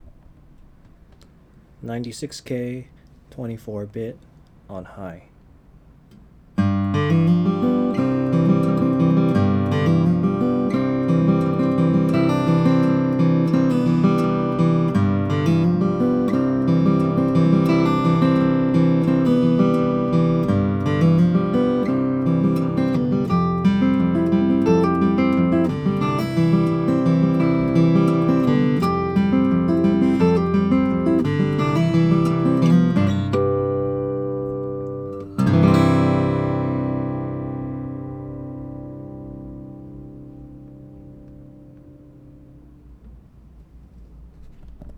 96kHz, front and back mics, mic pre set at High